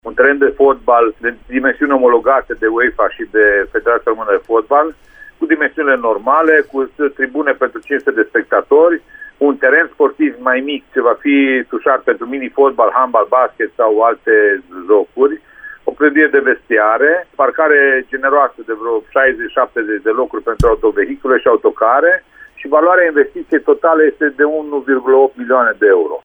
Primarul Ioan Popa a declarat, pentru Radio Reșița, că lucrările ar putea începe în această vară, iar baza va putea fi funcțională în toamna anului viitor. Edilul a precizat detaliile tehnice ale investiției realizate din fonduri guvernamentale:
Ioan-Popa-detaliile-proiectului.mp3